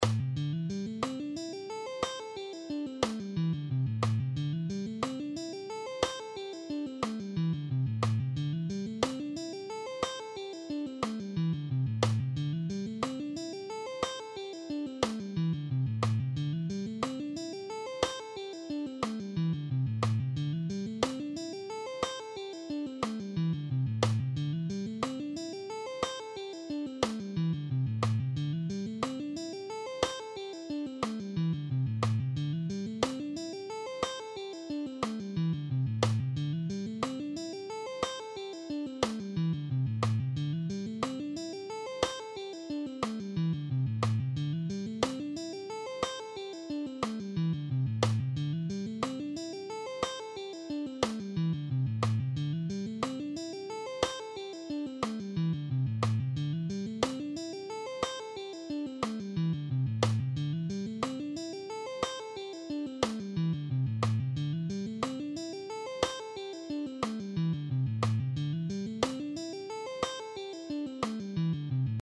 All these guitar exercises are in ‘C’
Pentatonic Sweep, Pentatonic Hammer-On Guitar Lesson
9.-Pentatonic-Sweep-Pentatonic-Hammer-On-Guitar-Lesson.mp3